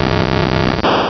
Cri de Spectrum dans Pokémon Diamant et Perle.